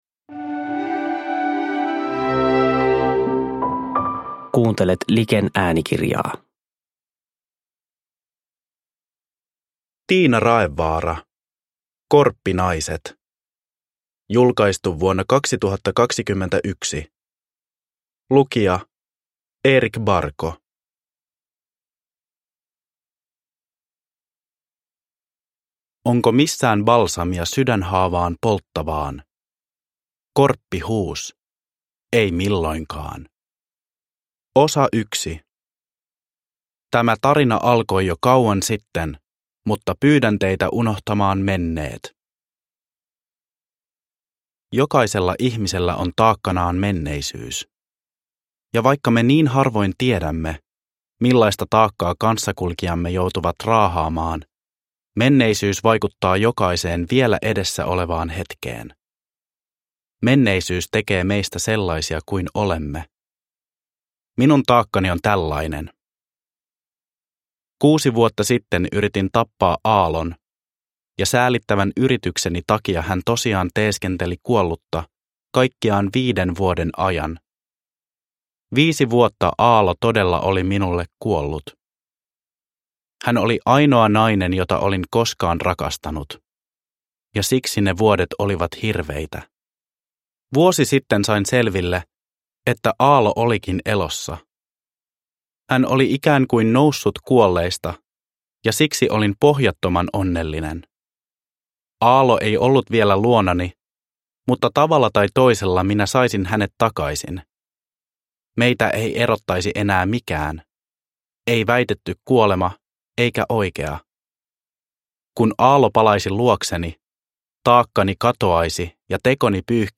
Korppinaiset – Ljudbok – Laddas ner